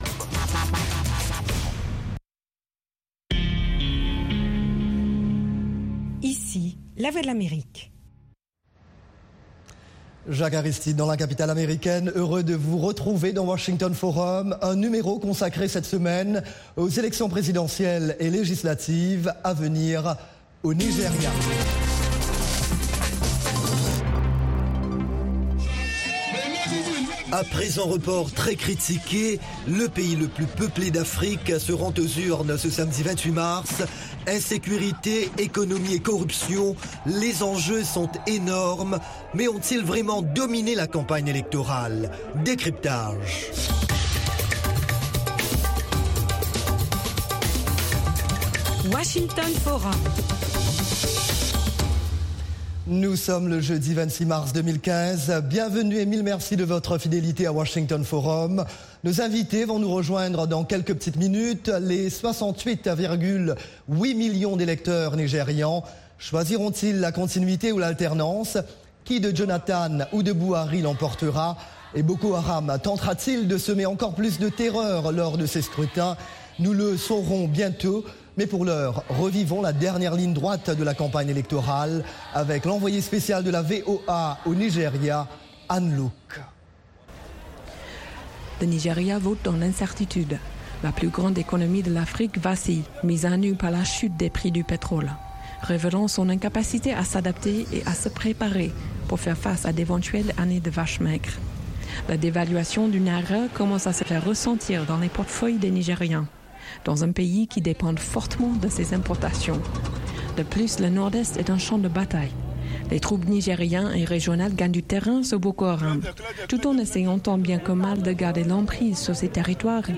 Economie, politique, santé, religion, sports, science, multimédias: nos experts répondent à vos questions en direct, via des Live Remote, Skype, et par téléphone de Dakar à Johannesburg, en passant par le Caire, New York, Paris et Londres. Cette émission est diffusée en direct par satellite à l’intention des stations de télévision et radio partenaires de la VOA en Afrique francophone.